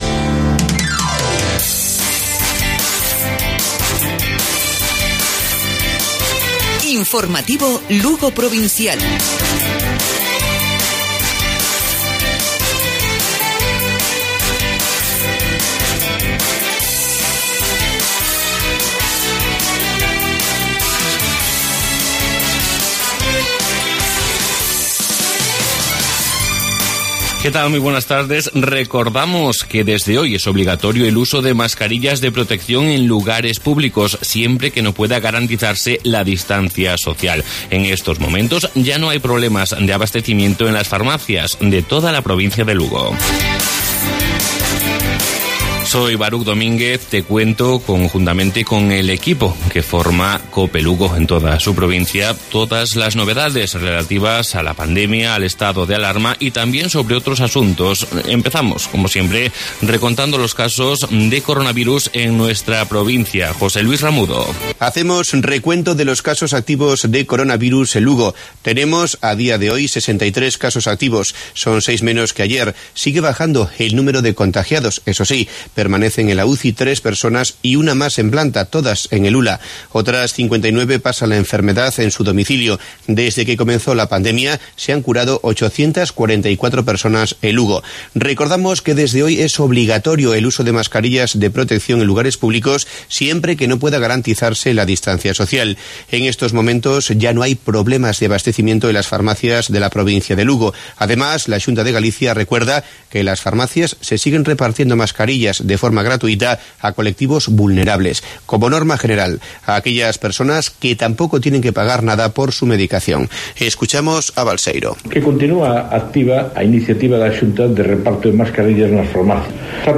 Informativo Provincial Cope.